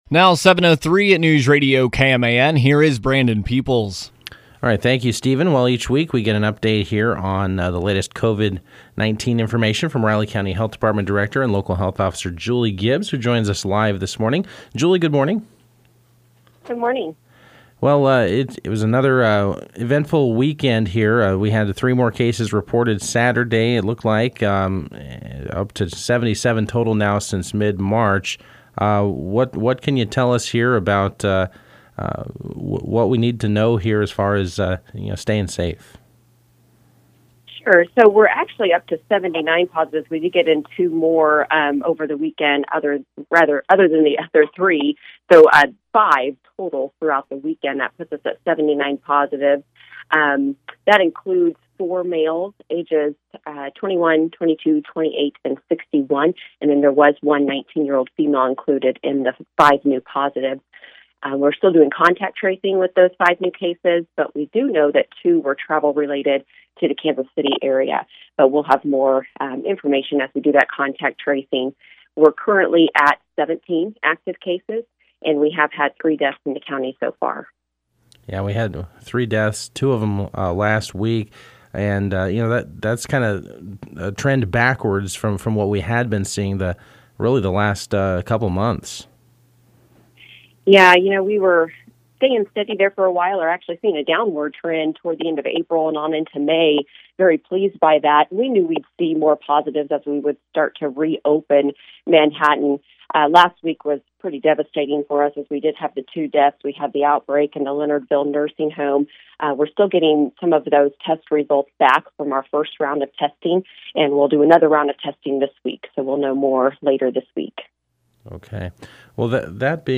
Health Officer Julie Gibbs joined KMAN in the 7 AM hour to provide our weekly COVID update.